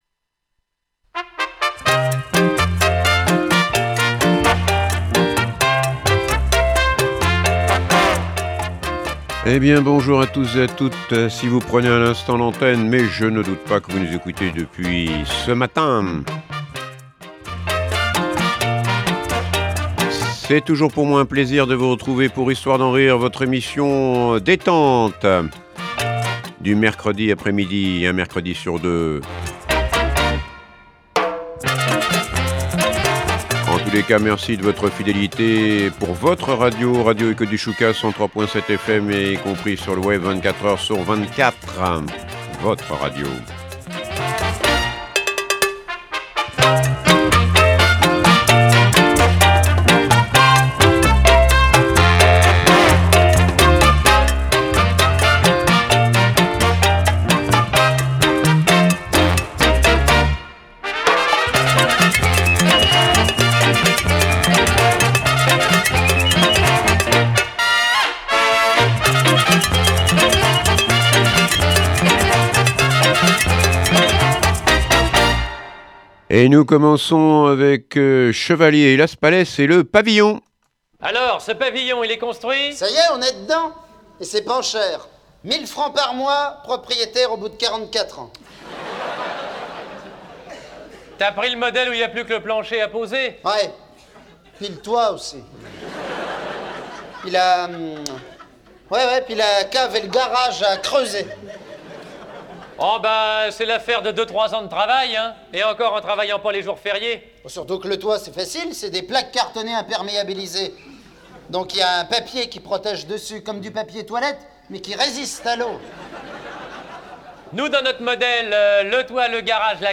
vous fait rire avec un tas de sketchs , des meilleurs humoristes français !